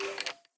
skeleton3.ogg